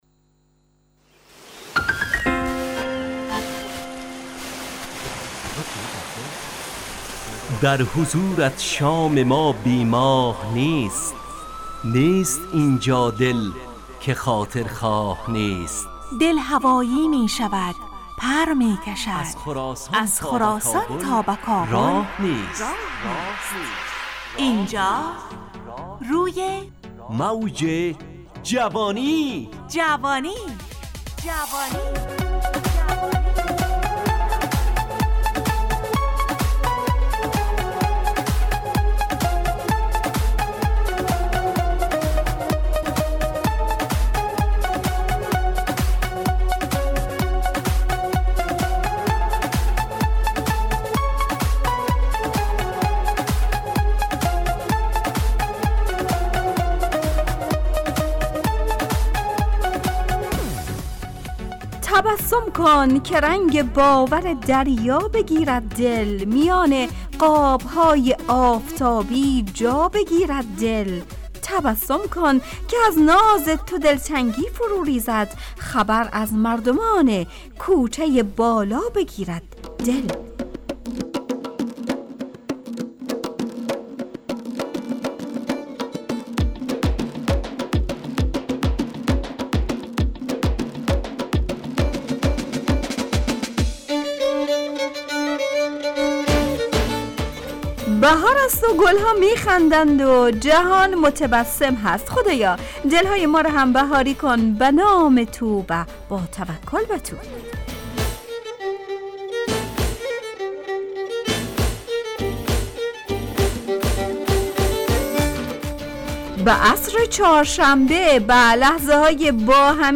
روی موج جوانی، برنامه شادو عصرانه رادیودری.
همراه با ترانه و موسیقی مدت برنامه 70 دقیقه .